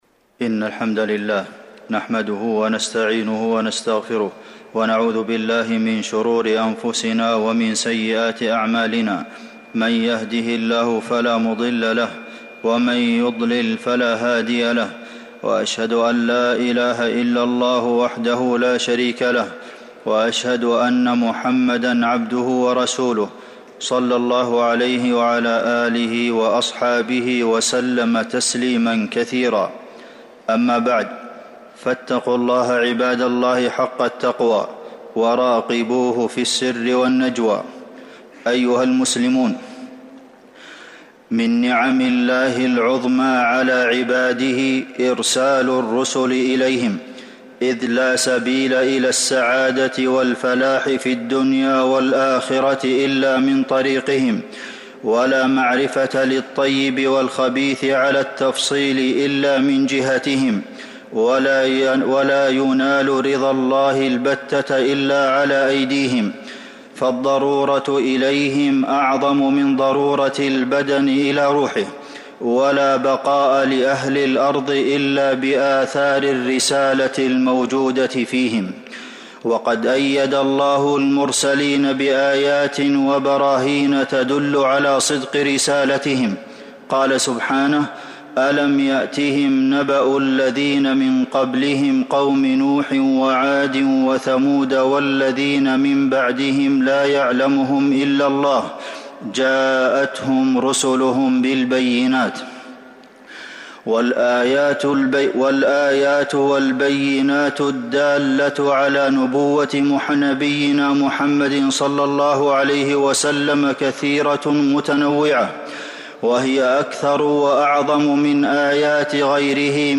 عرض السيرة من آيات القرآن الكريم. التصنيف: خطب الجمعة